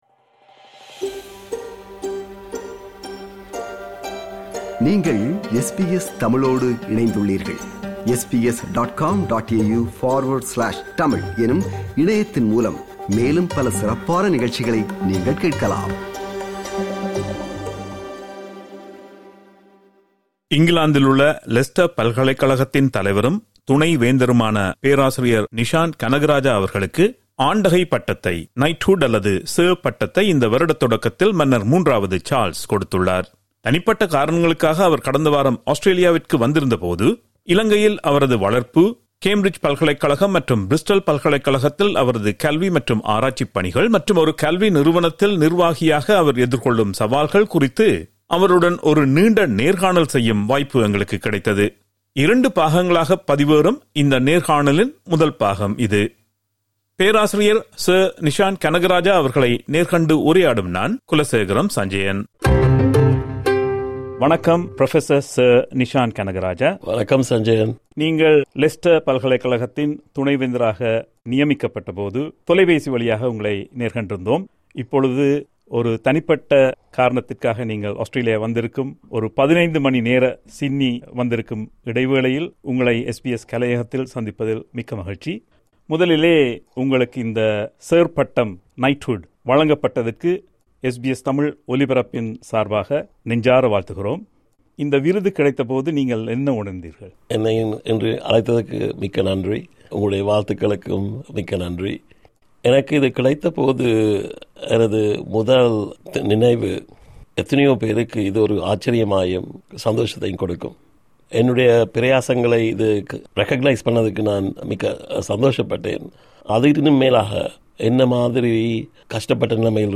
இலங்கையில் அவரது வளர்ப்பு, கேம்பிரிட்ஜ் பல்கலைக்கழகம், மற்றும் பிரிஸ்டல் பல்கலைக்கழகத்தில் அவரது கல்வி மற்றும் ஆராய்ச்சி பணிகள் மற்றும் ஒரு கல்வி நிறுவனத்தில் நிர்வாகியாக அவர் எதிர்கொள்ளும் சவால்கள் குறித்து அவருடன் ஒரு நீண்ட நேர்காணல் செய்யும் வாய்ப்பு எங்களுக்குக் கிடைத்தது. இரண்டு பாகங்களாகப் பதிவேறும் இந்த நேர்காணலின் முதல் பாகம் இது.
SBS Studios in Sydney, Australia